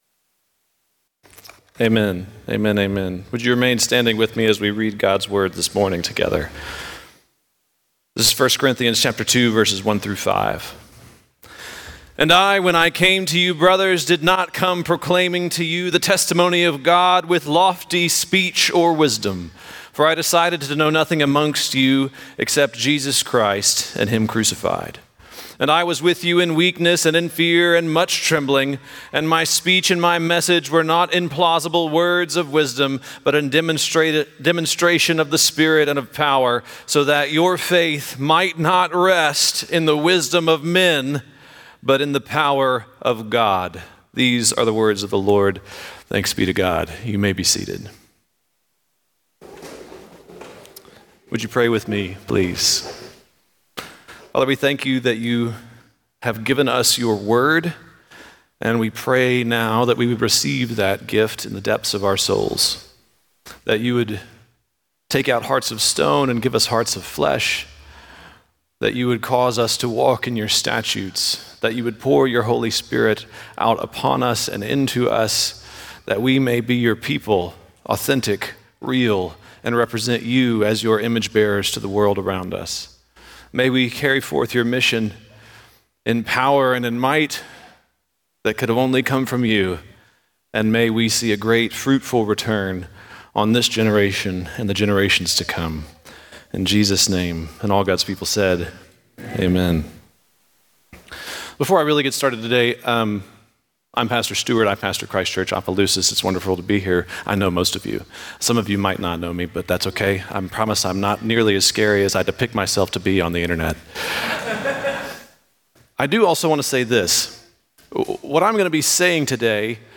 This is a part of our Sunday sermons.